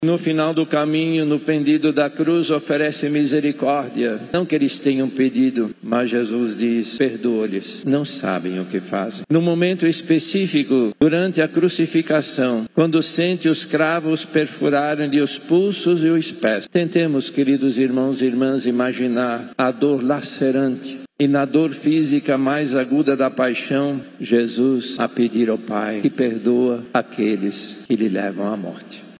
A missa teve continuidade dentro da igreja, a partir da liturgia da palavra. Em sua homilia, o Cardeal Steiner chamou a atenção para o despojamento de Jesus, aquele que se entrega por inteiro, sem negação e sem julgar aqueles que o ferem.
A celebração foi transmitida ao vivo pela rádio Rio Mar FM e redes sociais.